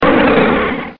P3D-Legacy / P3D / Content / Sounds / Cries / 369.wav